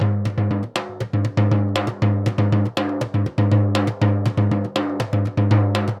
Surdo Baion 120_3.wav